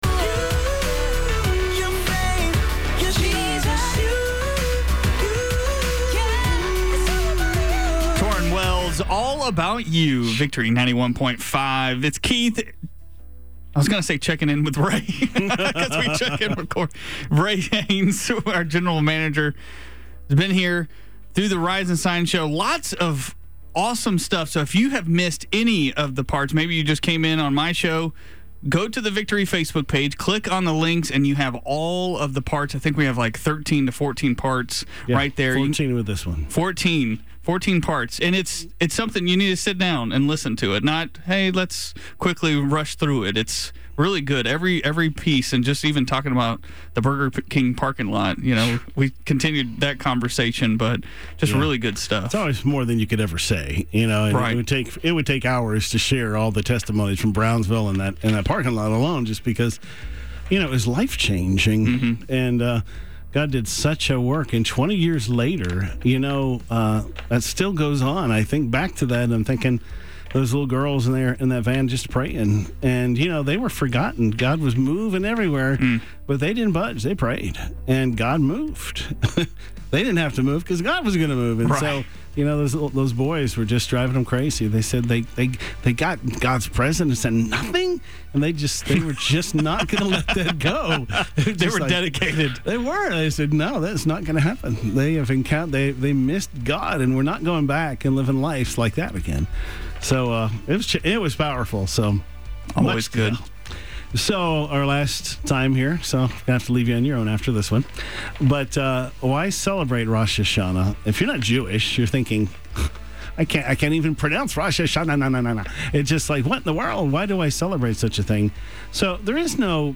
Here are the teaching notes used on the air: